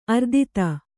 ♪ ardita